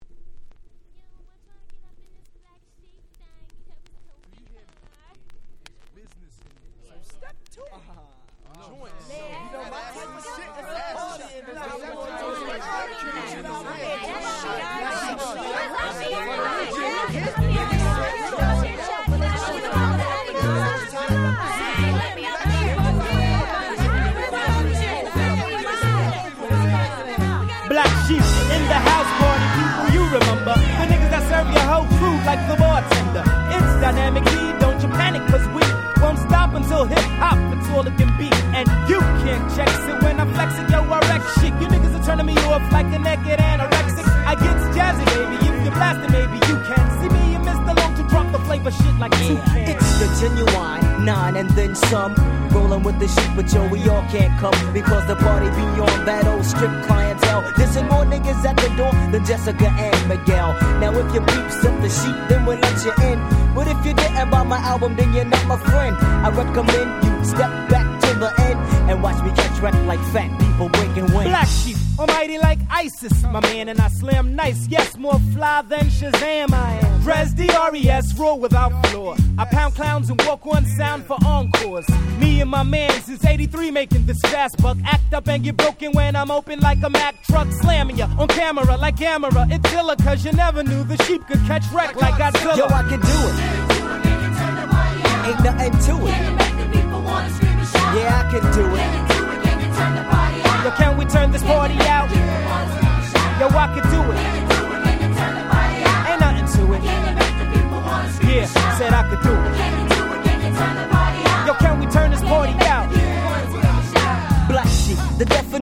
94' Smash Hit Hip Hop !!
Boom Bap